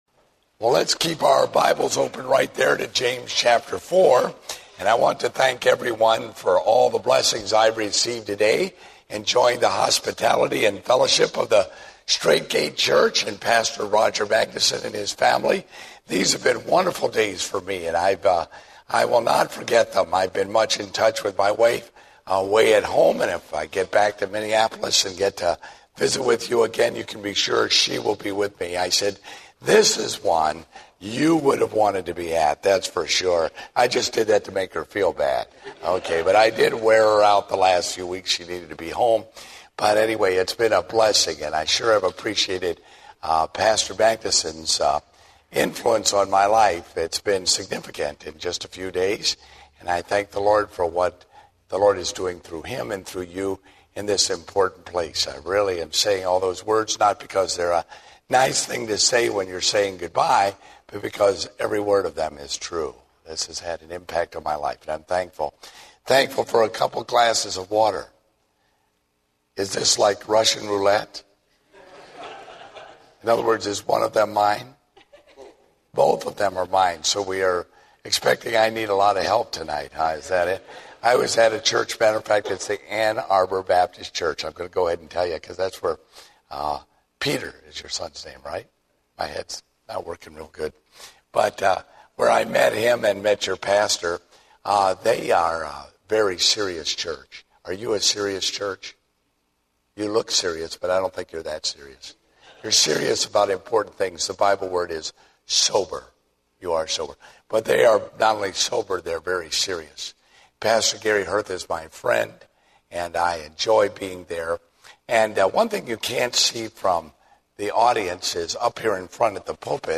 Date: August 29, 2010 (Evening Service)